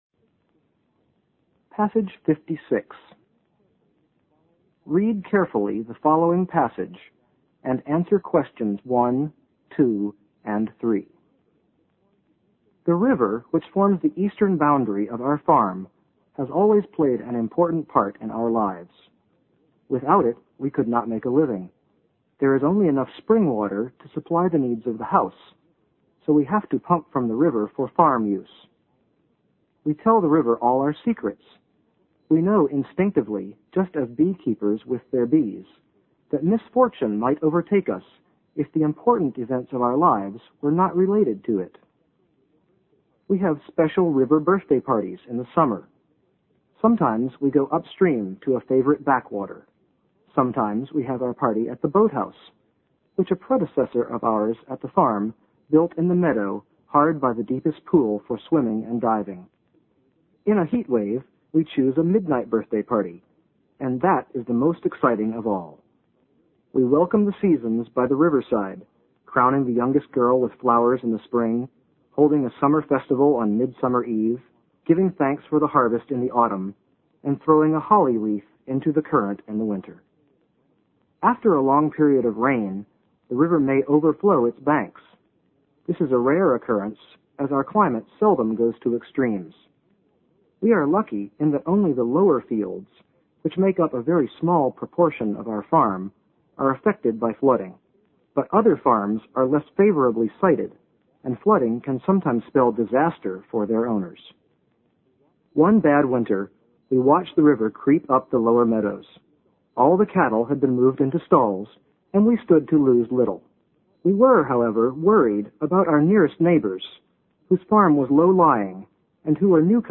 新概念英语85年上外美音版第三册 第56课 听力文件下载—在线英语听力室